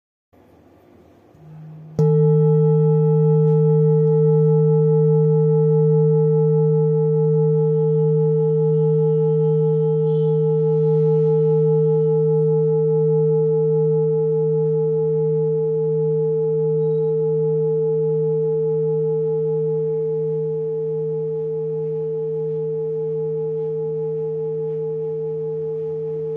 Buddhist Hand Beaten Jambati Singing Bowl, with Plain, Select Accessories
Material Bronze